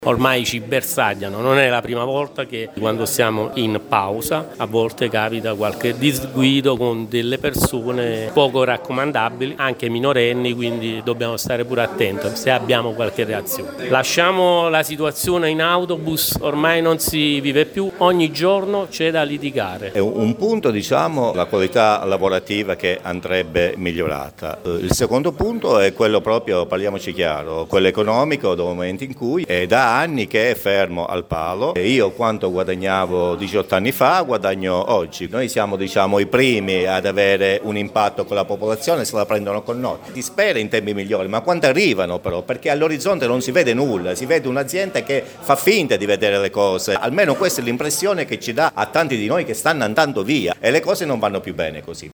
Un ulteriore episodio dunque ai danni degli autisti di Seta che denunciano condizioni di lavoro ormai intollerabili, qui sotto le testimonianze